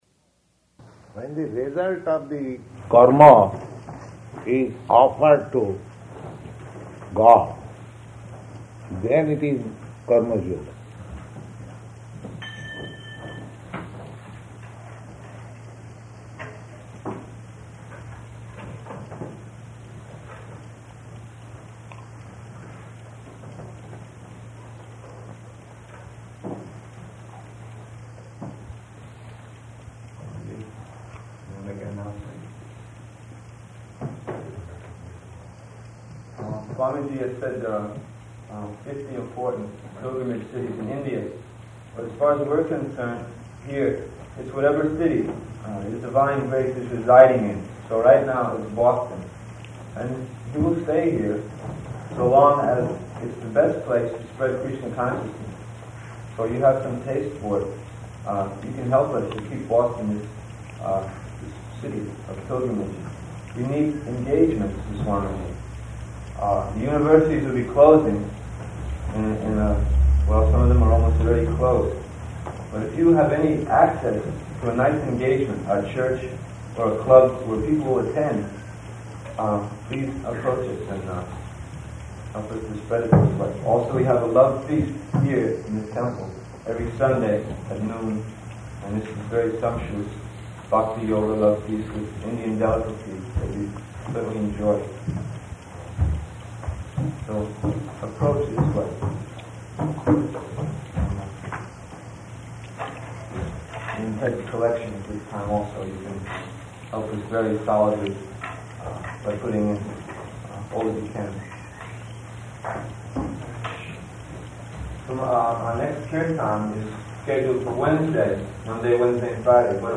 Talk After Lecture
Location: Boston